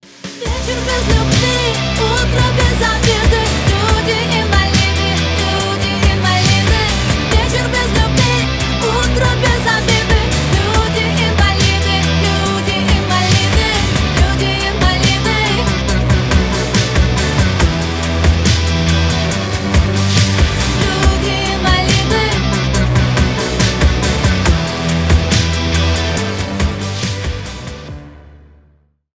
Рок Металл
кавер